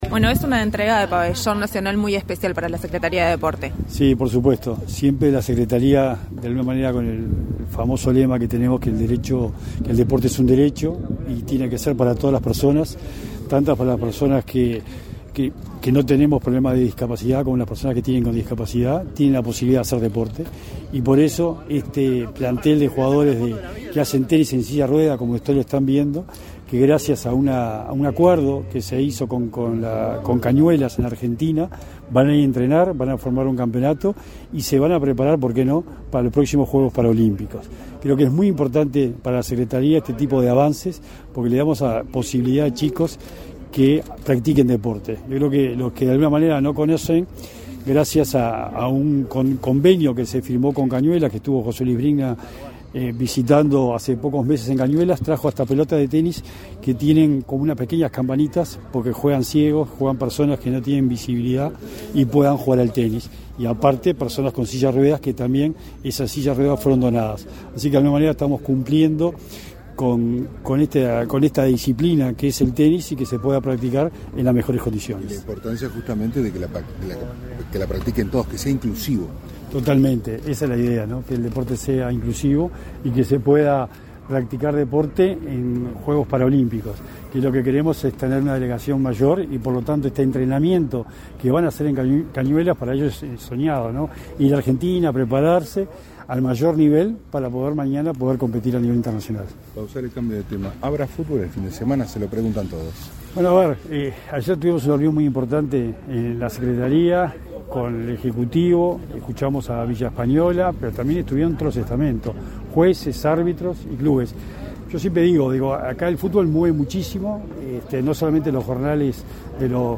Declaraciones a la prensa del secretario nacional del Deporte, Sebastián Bauzá
Tras el evento, Bauzá efectuó declaraciones a la prensa.